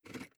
gearShift.wav